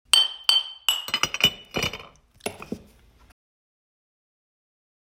A new segment of the "Glass Break 2" audio file. No further effects were added. This sound is correlated with the letter "g" on the computer keyboard.